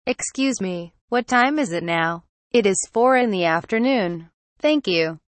Conversation Dialog #1: